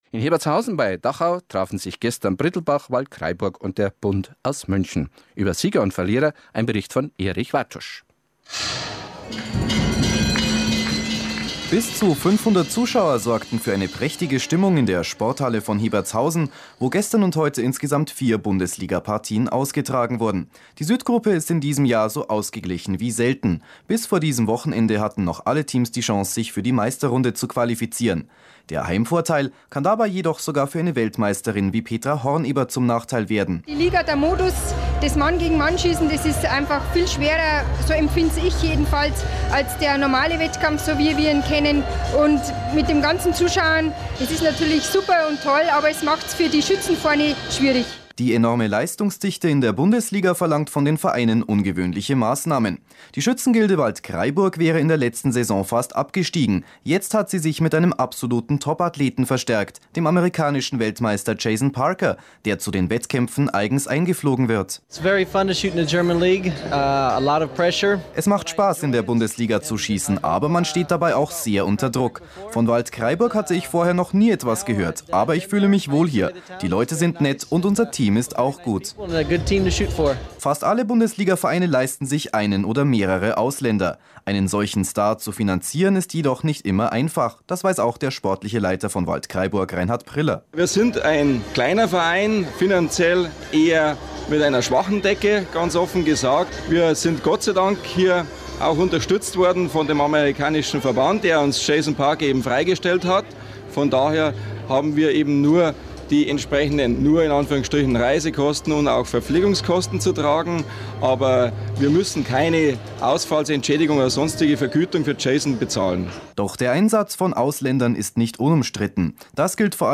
Der Bayerische Rundfunk berichtete am 1.12.2002 im Programm Bayern 2 zwischen 17.00 und 18.00 Uhr vom Bundesliga-Wettkampf in Hebertshausen.